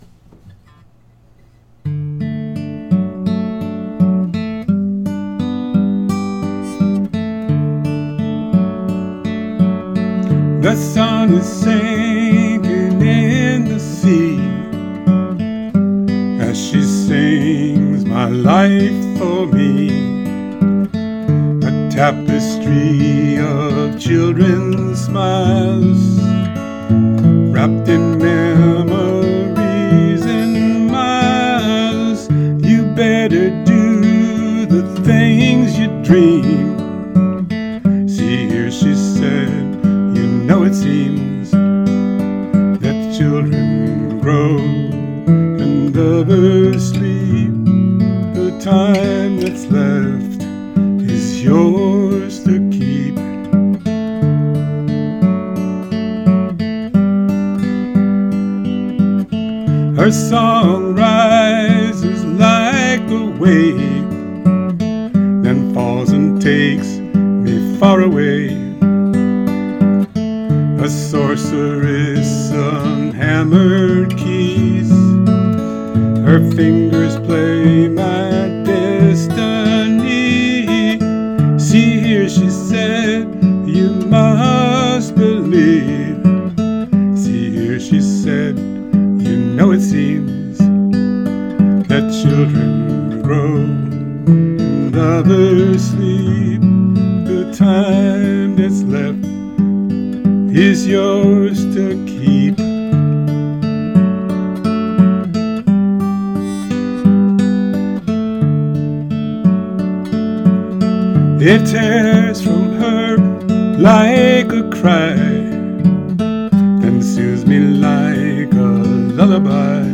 Here's my cover of the song.